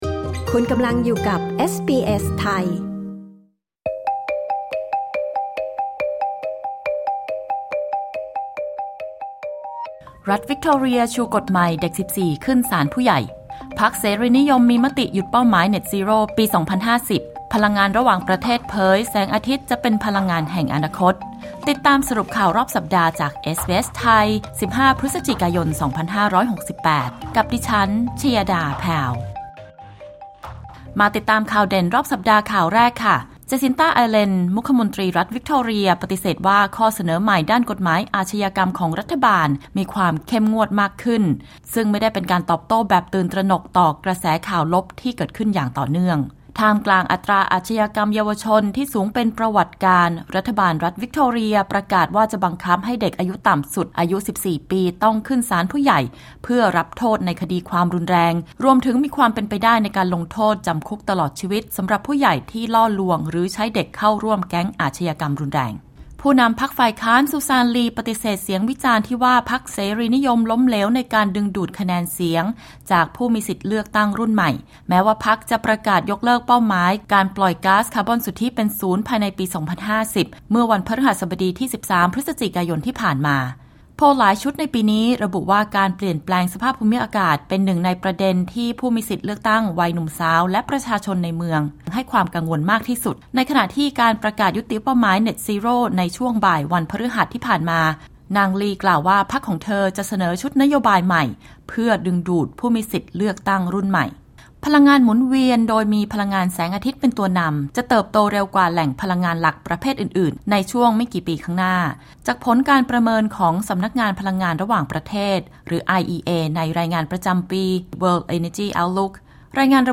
สรุปข่าวรอบสัปดาห์ 15 พฤศจิกายน 2568